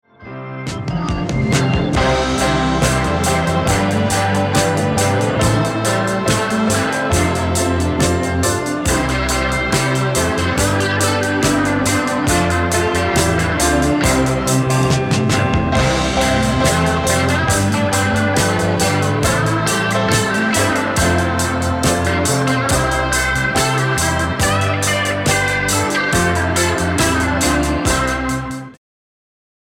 Gitarrensolo